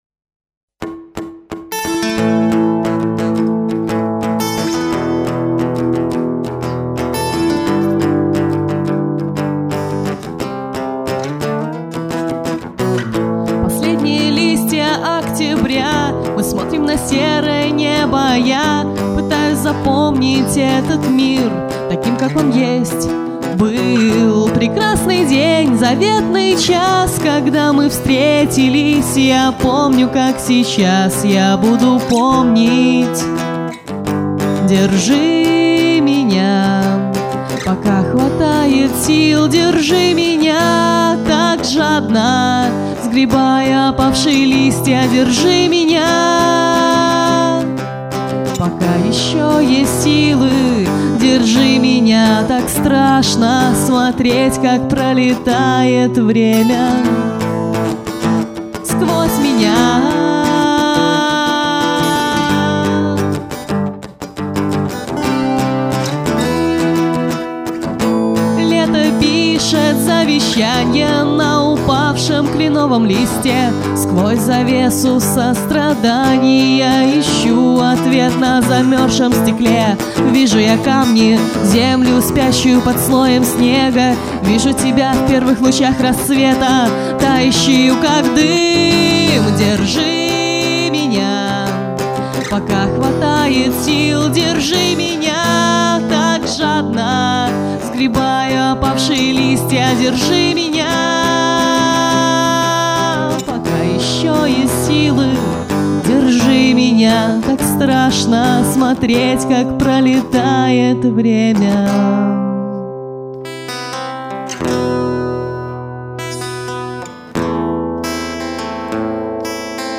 Концертная версия композиции